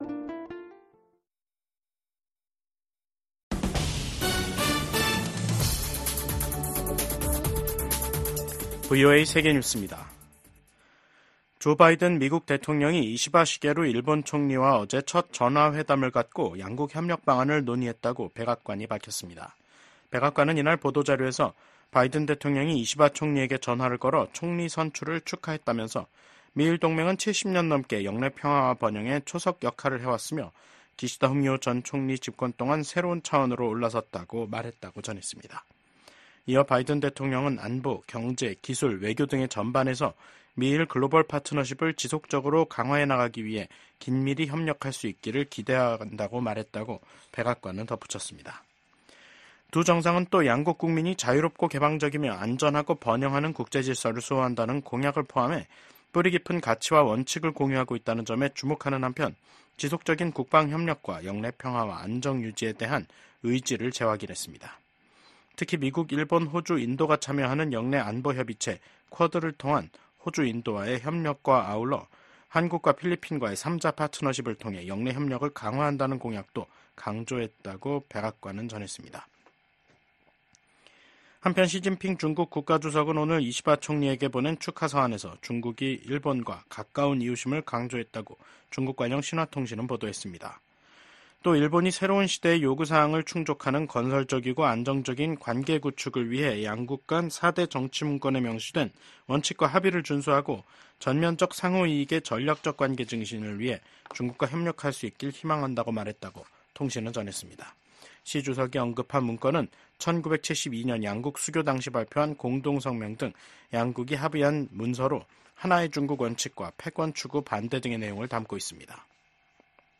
VOA 한국어 간판 뉴스 프로그램 '뉴스 투데이', 2024년 10월 2일 2부 방송입니다. 민주당의 팀 월즈 부통령 후보와 공화당의 J.D. 밴스 후보가 첫 TV 토론에서 안보와 경제, 이민 문제를 두고 첨예한 입장 차를 보였습니다. 토니 블링컨 미국 국무장관은 북한, 중국, 러시아, 이란을 국제 질서를 흔드는 세력으로 규정하며 동맹과 단호히 대응해야 한다고 밝혔습니다.